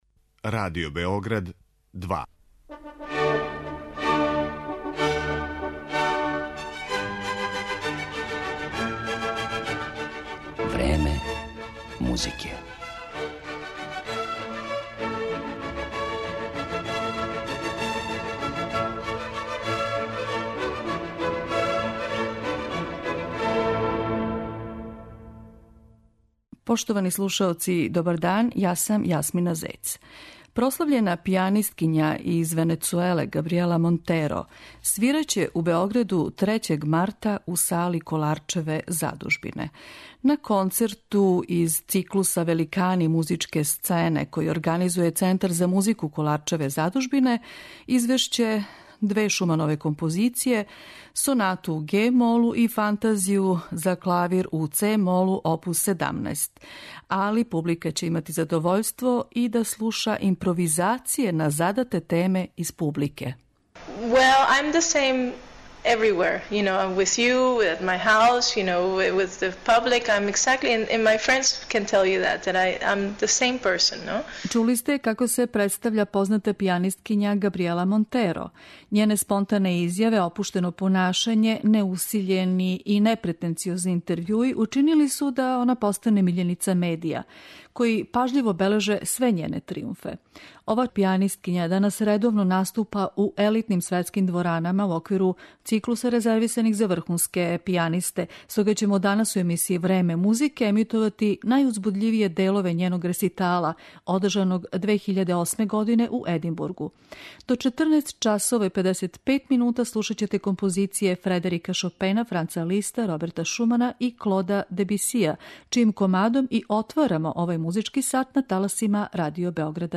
Габријела Монтеро, прослављена пијанисткиња из Венецуеле, свираће у Београду, у сали Колaрчеве задужбине, 3. марта.
Тим поводом, данашњу емисију посвећујемо Габријели Монтеро, коју ћемо представити као изврсног интерпретатора композиција Клода Дебидија, Роберта Шумана, Фредерика Шопена и Франца Листа.